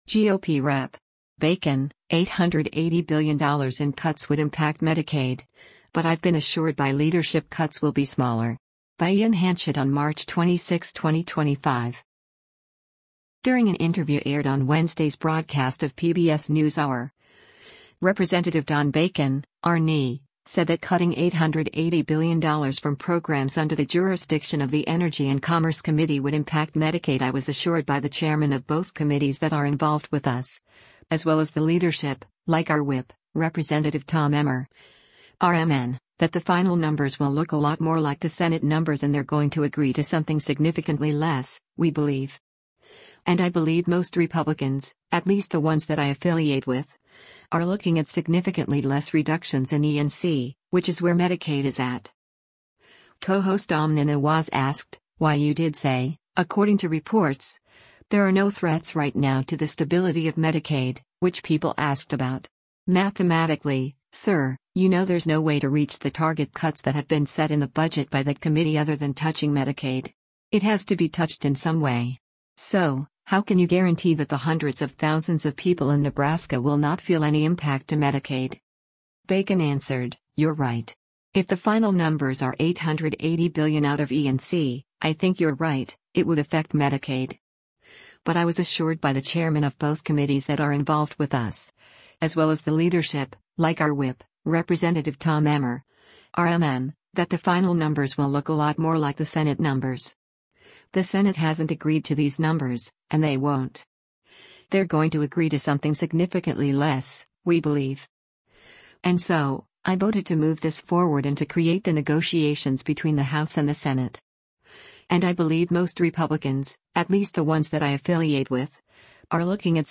During an interview aired on Wednesday’s broadcast of “PBS NewsHour,” Rep. Don Bacon (R-NE) said that cutting $880 billion from programs under the jurisdiction of the Energy and Commerce Committee would impact Medicaid “I was assured by the chairmen of both committees that are involved with us, as well as the leadership, like our Whip, Rep. Tom Emmer (R-MN), that the final numbers will look a lot more like the Senate numbers” and “They’re going to agree to something significantly less, we believe.”